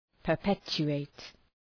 Προφορά
{pər’petʃu:,eıt}